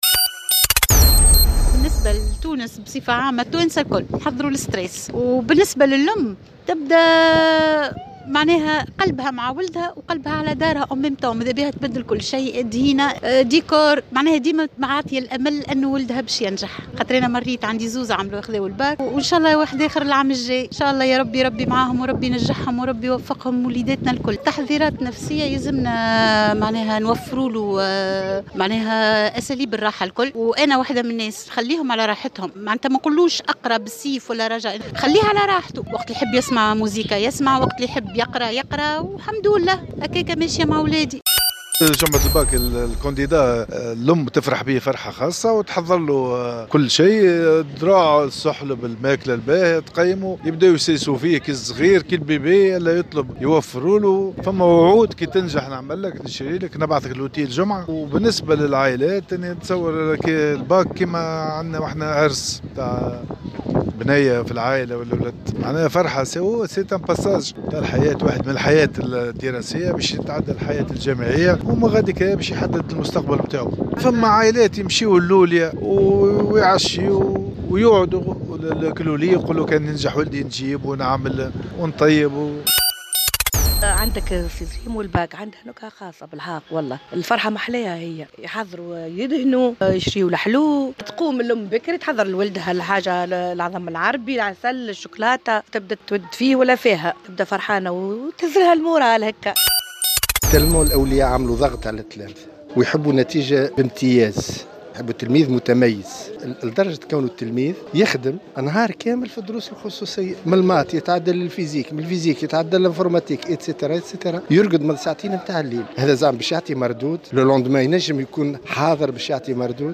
ودعا بعض الأولياء في تصريحات لـ"الجوهرة أف أم"، إلى ضرورة مراعاة الجانب النفسي للأبناء وتجنب ممارسة الضغط عليهم وحثهم باستمرار على المراجعة ليلا نهارا وخاصة ليلة الامتحانات، مشيرا إلى أن ذلك لن يُعطي المردود والنتائج المرجوّة. وقال شقّ آخر إن لامتحانات البكالوريا نكهة خاصة، حيث يقومون بالاستعداد لهذه الفرحة وشراء الحلويات وطلاء المنزل وتغيير الديكور..